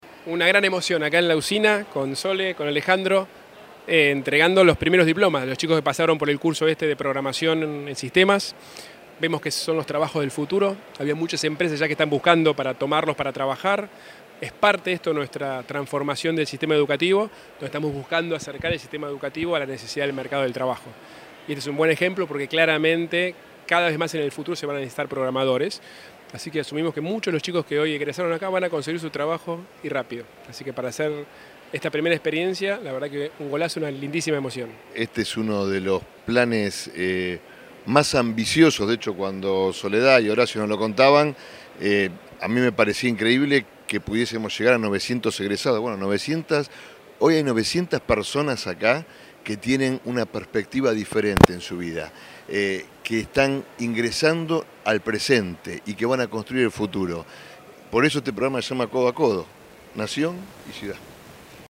El jefe de Gobierno porteño, Horacio Rodríguez Larreta; el ministro de Educación de la Nación, Alejandro Finocchiaro, y la ministra de Educación de la Ciudad, Soledad Acuña, entregaron diplomas a egresados de "Codo a Codo", el curso gratuito de programación que ofrece la Ciudad.